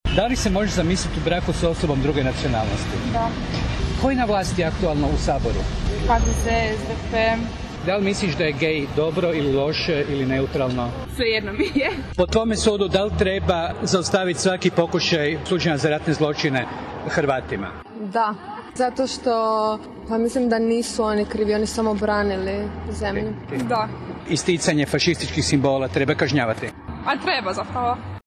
Anketa: Mladi Zagrepčani o politici